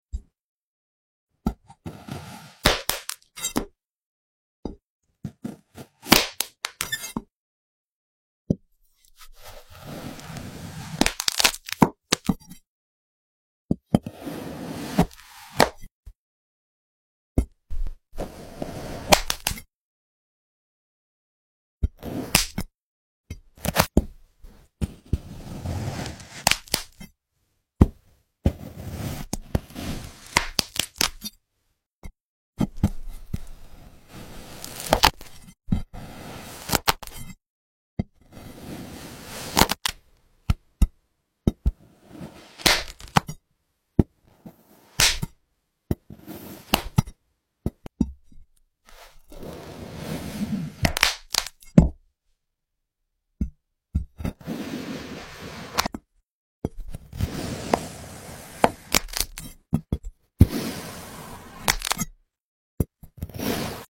Sports logos sliced like rubber